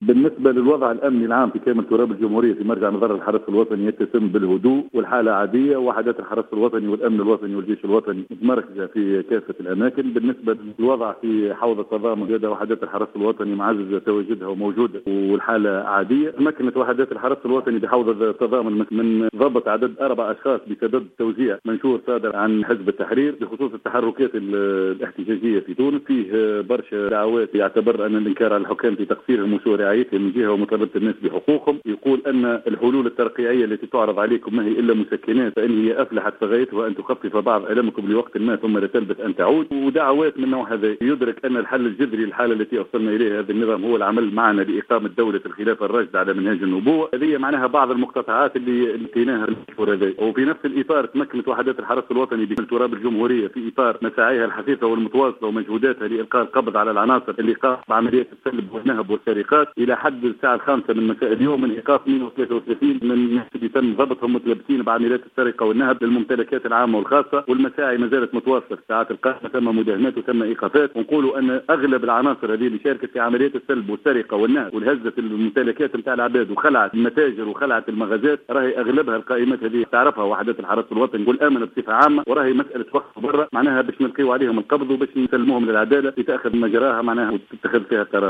وأكد في تصريح لـ "الجوهرة أف ام"، أن هذه المناشير صادرة عن حزب التحرير وتتضمن دعوات للتعاون مع الحزب لإقامة دولة الخلافة، بحسب تعبيره.